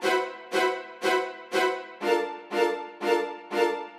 Index of /musicradar/gangster-sting-samples/120bpm Loops
GS_Viols_120-C.wav